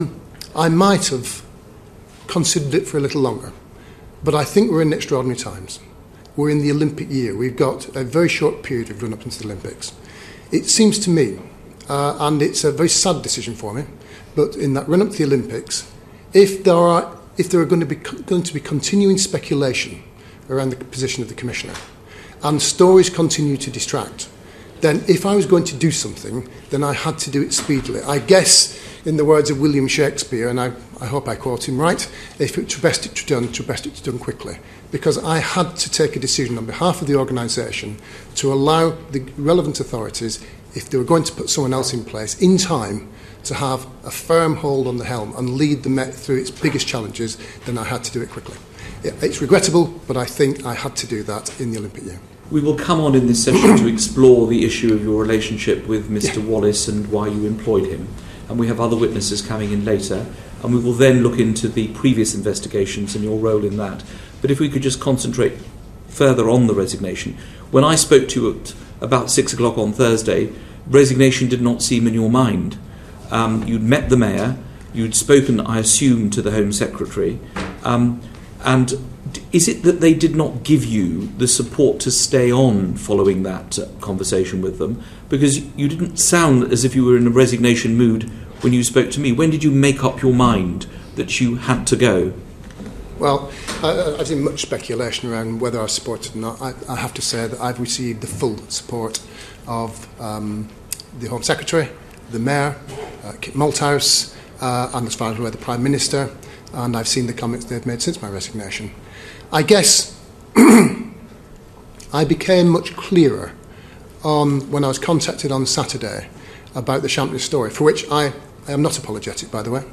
Sir Paul Stephenson snippet from select committee